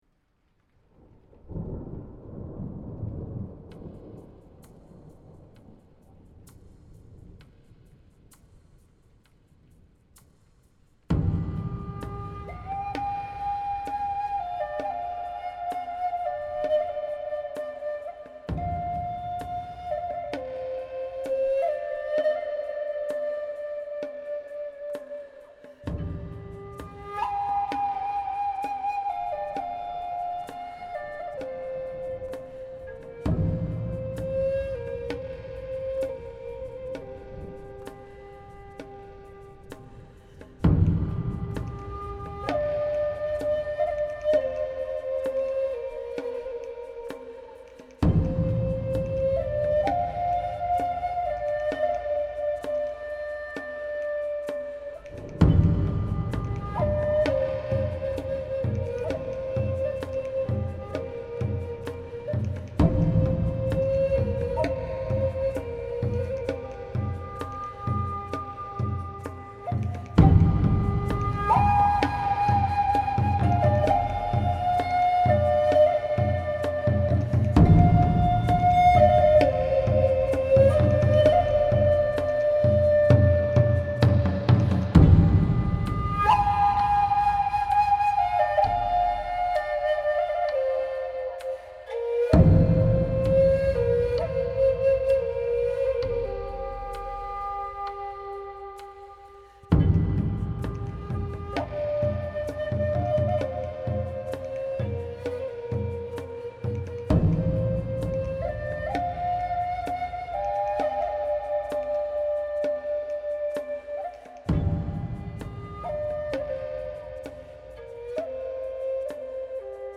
Eeeek Input Please - Native Flute Mix
I had some help with a drum track, then threw in some mixing of my own - all in Garageband.
This is my rendition of "Lakota Lullaby" - Im really wondering if the drums are overpowering the flute(s) Also - do you think it would be better with just a single flute instead of layering flutes? Maybe less echo on the flute(s) ?